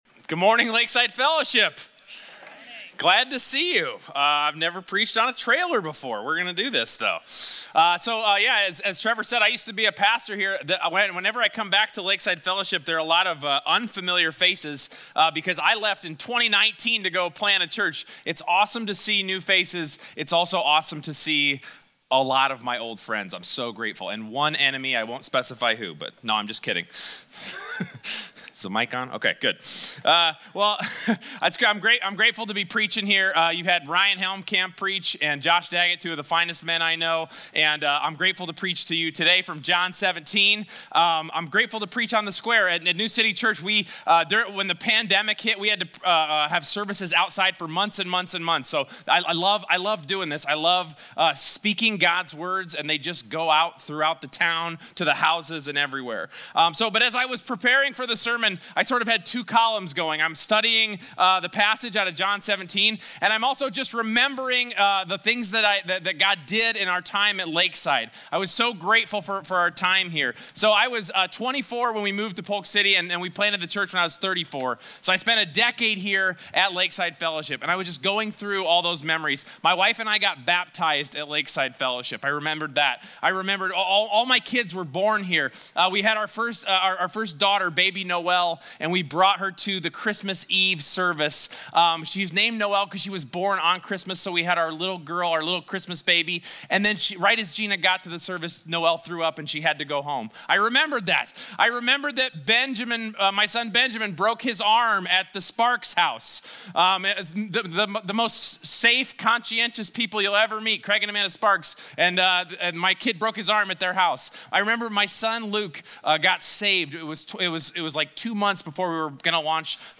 Sermons 20 Year Anniversary Celebration